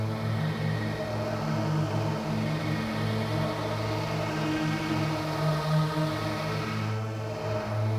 STK_Drone4Proc06_A.wav